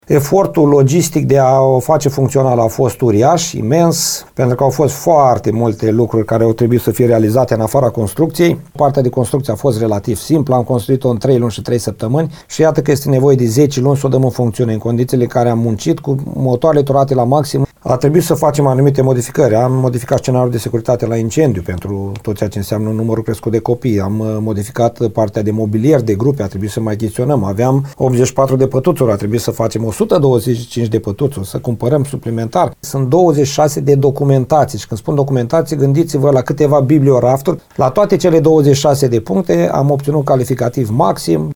Primarul CĂTĂLIN COMAN a precizat că numărul locurilor de la creșă a fost majorat de la 80 la 125, datorită numeroaselor solicitări depuse de către localnici.